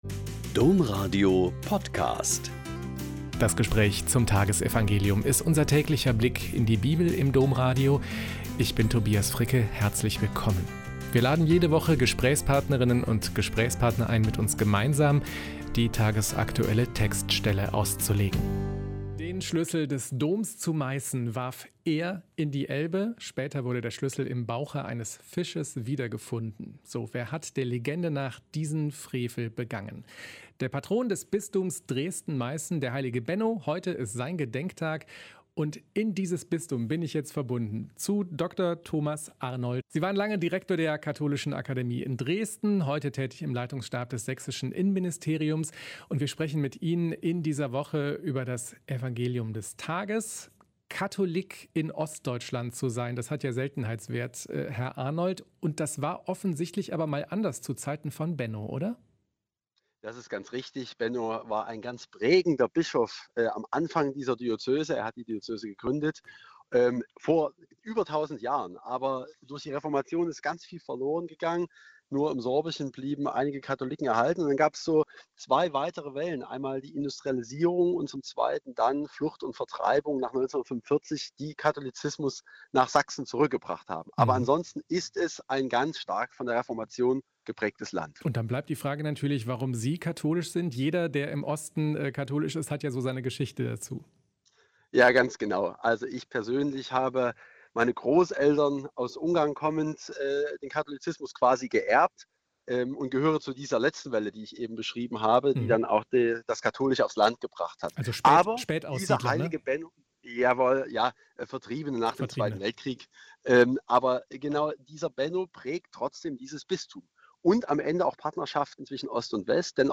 Mt 5,38-42 - Gespräch